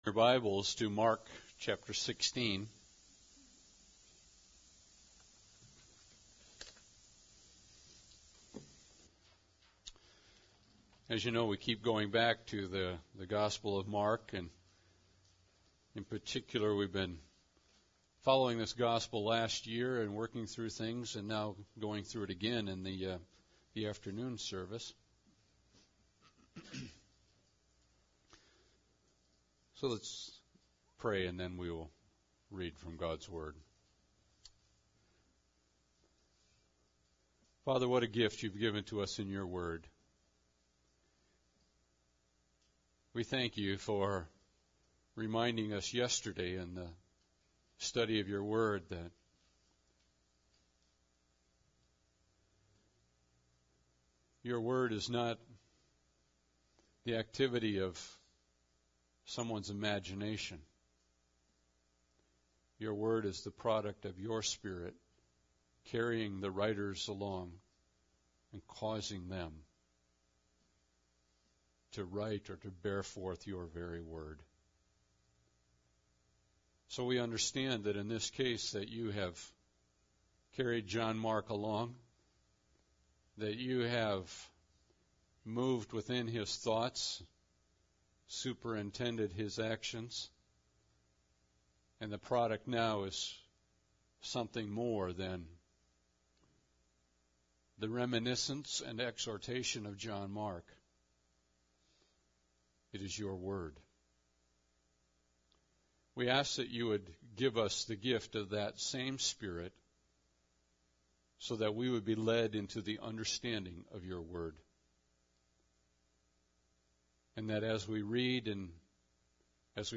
Mark 16:1-8 Service Type: Sunday Service Bible Text